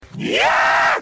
Cod Zombie Scream